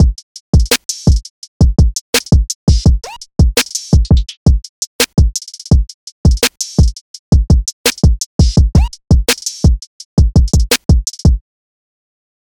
Cardiak_Drum_Loop_3_168bpm.wav